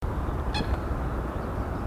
Common Gallinule (Gallinula galeata)
Life Stage: Adult
Location or protected area: Reserva Ecológica Costanera Sur (RECS)
Condition: Wild
Certainty: Observed, Recorded vocal